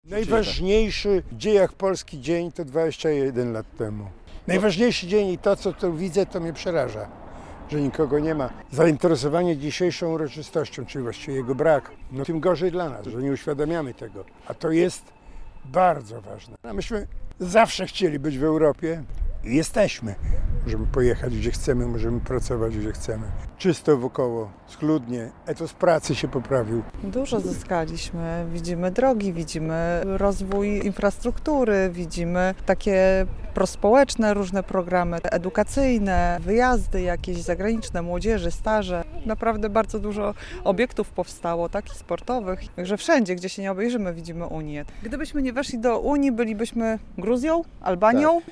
Mieszkańcy Białegostoku o rocznicy i braku przedstawicieli władz